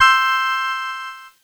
Cheese Chord 18-C4.wav